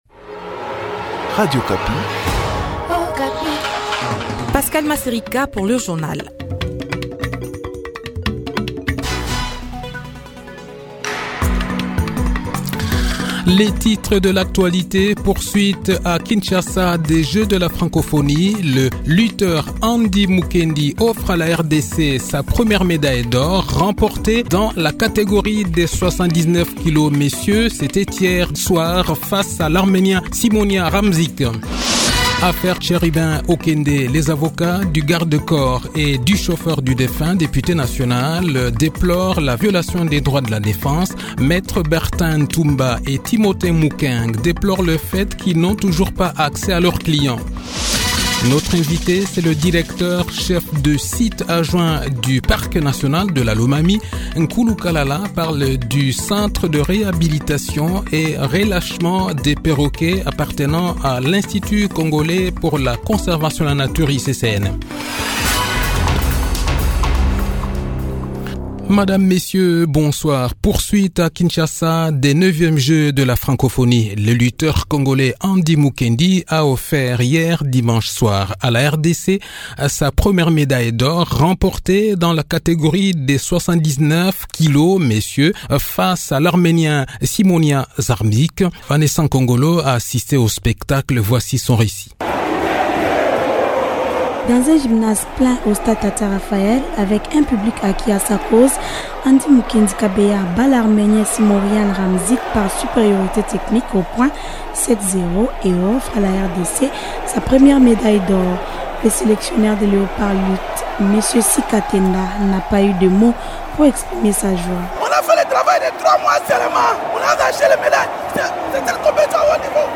Le journal de 18 h, 31 juillet 2023